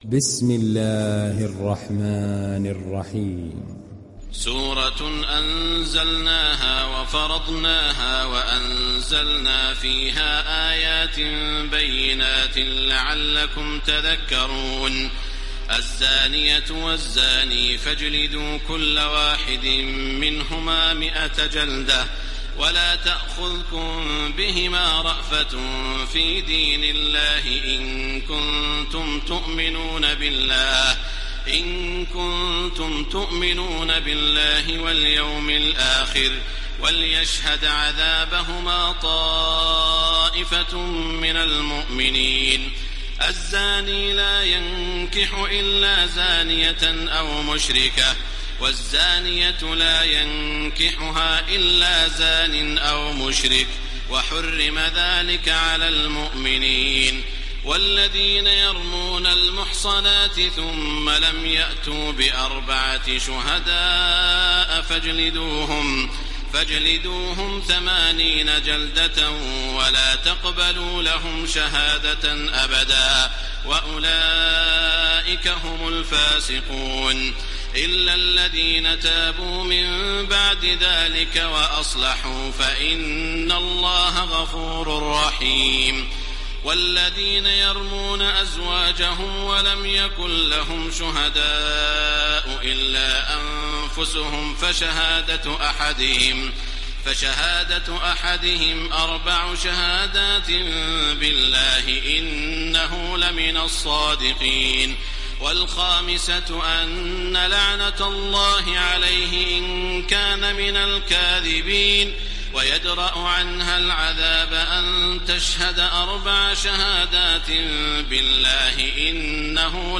ডাউনলোড সূরা আন-নূর Taraweeh Makkah 1430